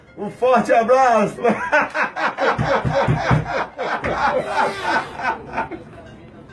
Bolsonaro Risada Sound Button - Free Download & Play